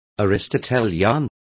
Also find out how peripatetico is pronounced correctly.